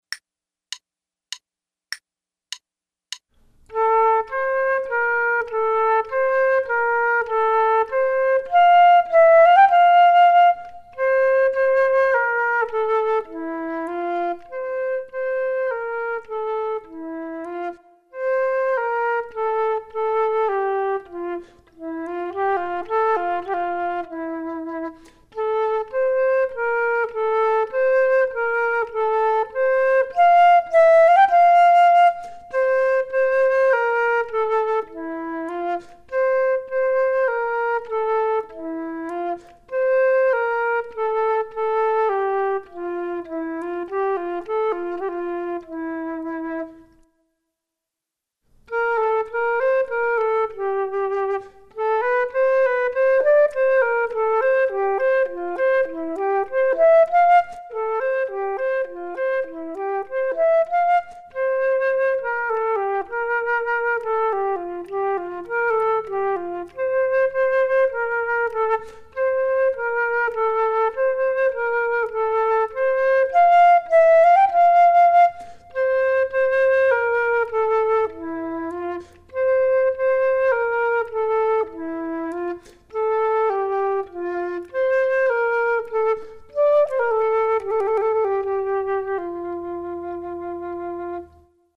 Flute 2 Only: